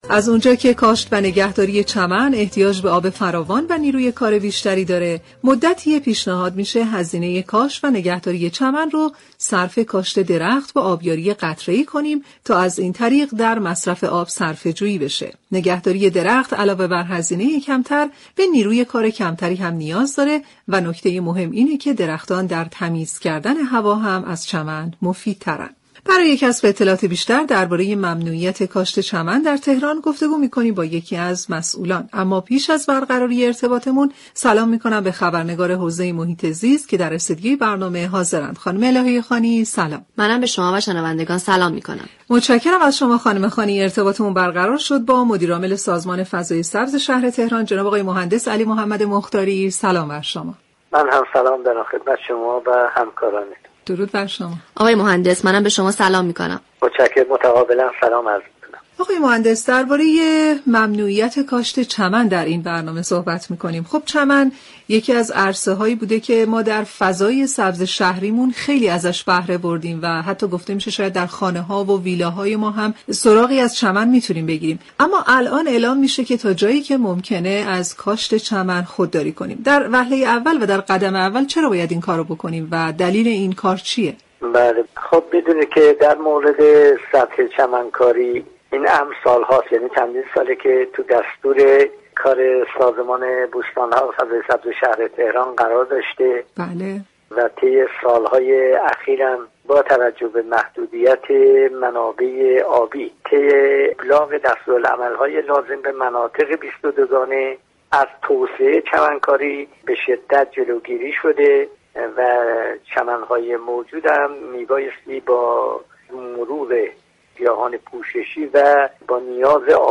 مهندس علی محمد مختاری مدیر عامل سازمان فضای سبز شهر تهران در برنامه سیاره آبی